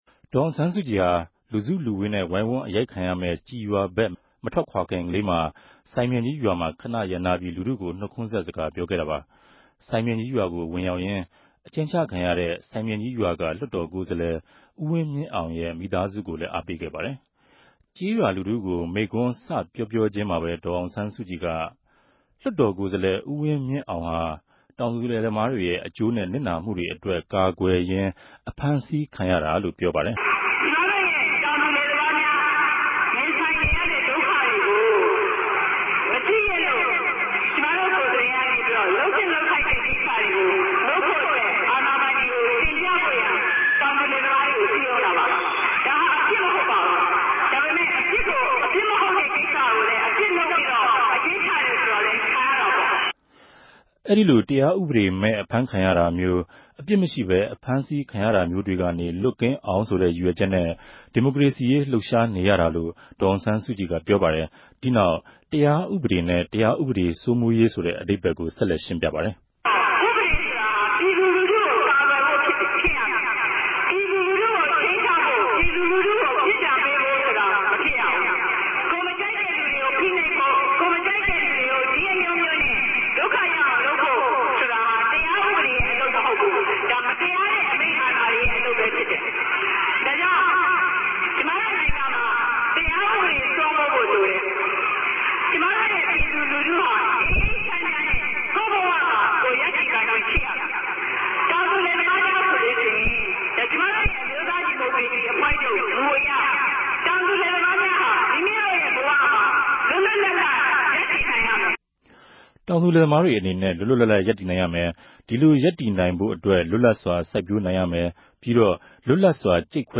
၂၀၀၃ ခု မေလ ၃၀ ရက်နေႛက ဒေၞအောင်ဆန်းစုုကည် စိုင်ူပင်ဋ္ဌကီး လူထု ေူပာခဲ့တဲ့ မိန်ႛခြန်းဟာ ဒီပဲယင်းမရောက်ခင် နောက်ဆုံး ေူပာခဲ့တဲ့ မိန်ႛခြန်းလည်း ူဖစ်ပၝတယ်။ ဒီမိန်ႛခြန်းကို သံဃာတော်တပၝးက အသံဖမ်းယူထားတာ ူဖစ်တဲ့အတြက် အသုံကည်လင်မမြရြိတာကို သည်းခံစေလိုပၝတယ်။